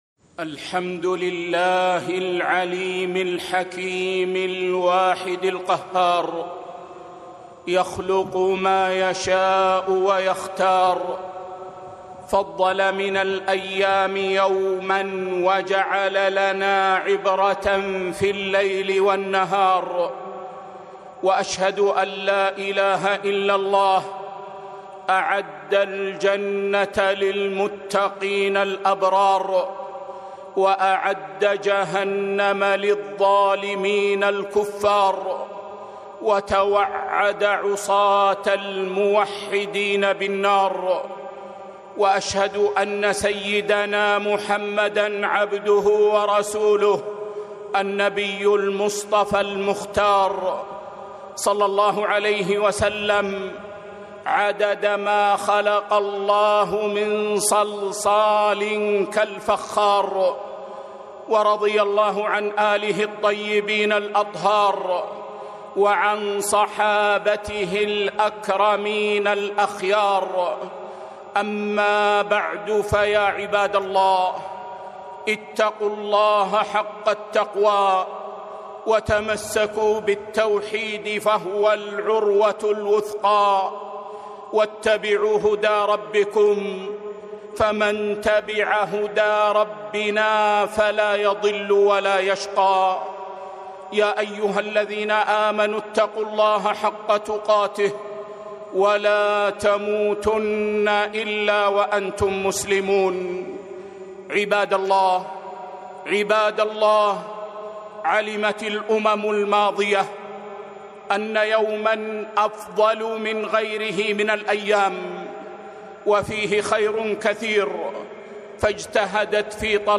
خطبة - من غنائم يوم الجمعة